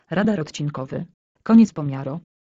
Dźwięki ostrzegawcze Radar Odcinkowy Koniec
Dźwięki ostrzegawcze Radar Odcinkowy Koniec Pobierz gotowe komunikaty głosowe / alarmy ostrzegawcze w formacie MP3.